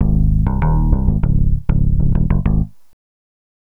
Swinging 60s 6 Bass-G#.wav